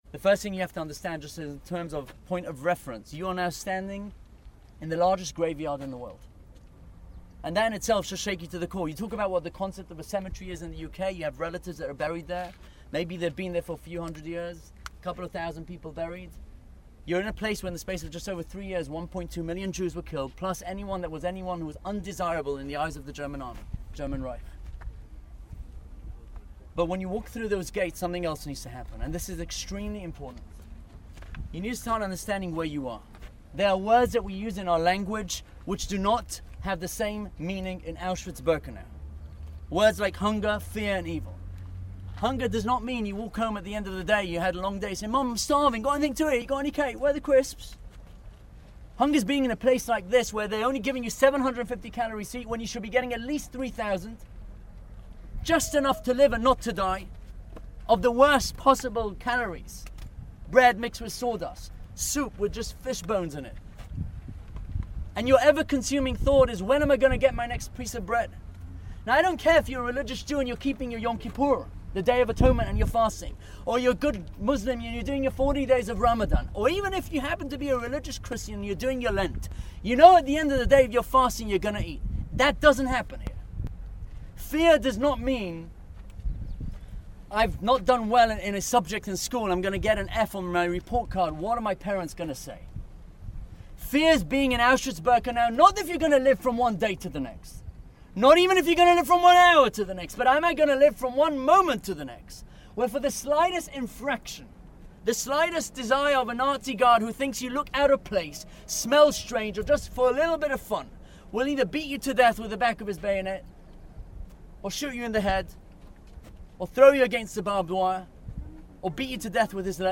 students visiting the Auschwitz-Birkenau death camp in Poland